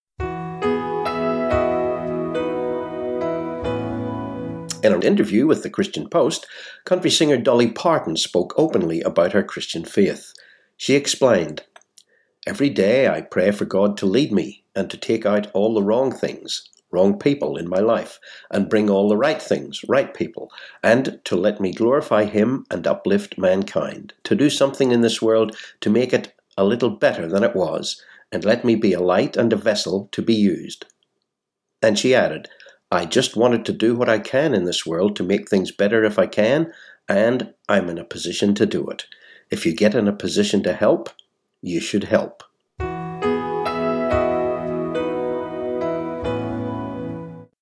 Listen to this news item on audio player